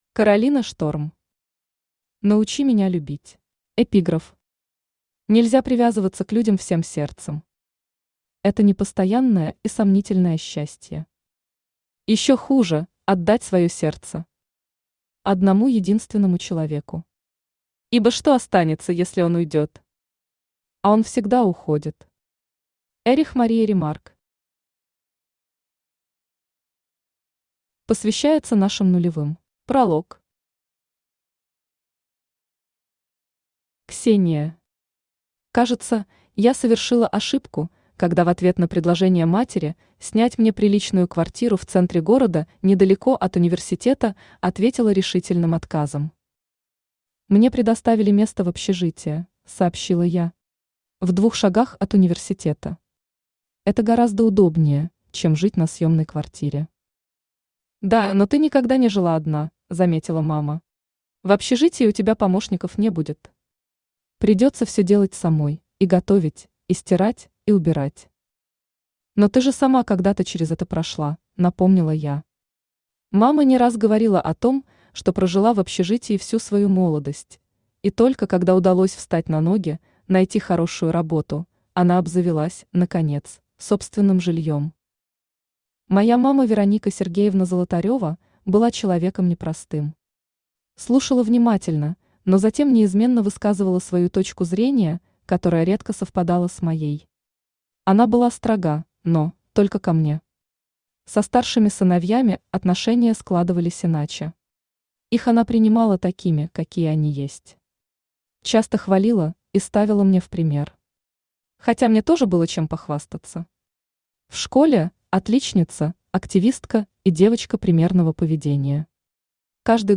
Aудиокнига Научи меня любить Автор Каролина Шторм Читает аудиокнигу Авточтец ЛитРес.